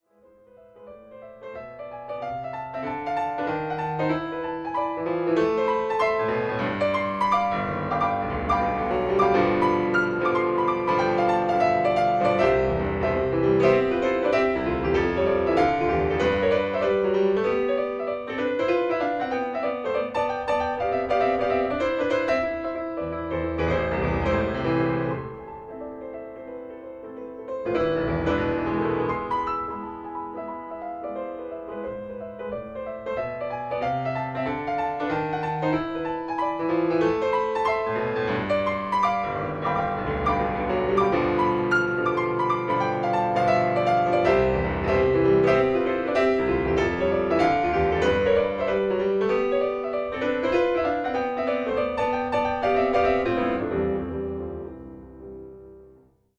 Stereo
Recorded January 2013, Yamaha Artist Services, New York, USA